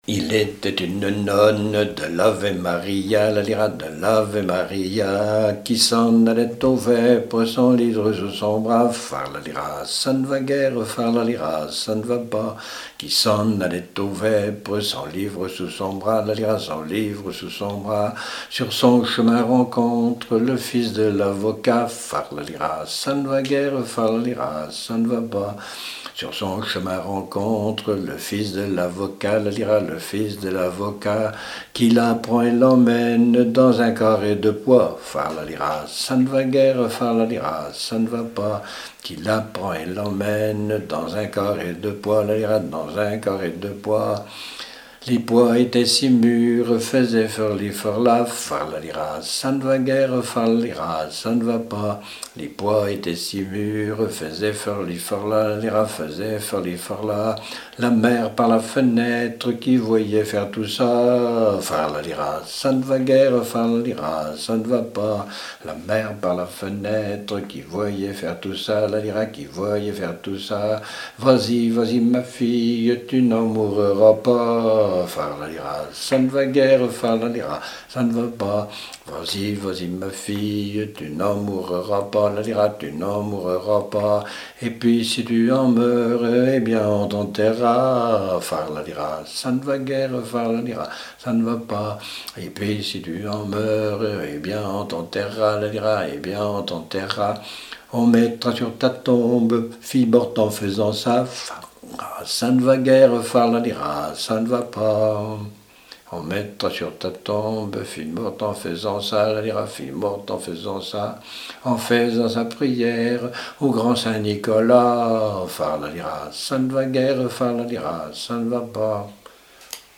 Genre laisse
Répertoire de chants brefs pour la danse